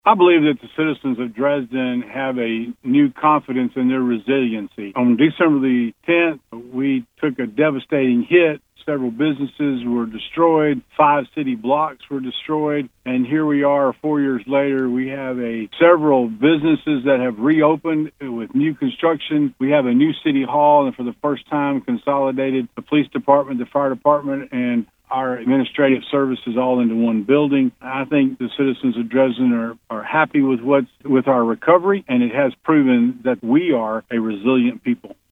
Mayor Maddox tells us how Dresden has changed since that horrific night…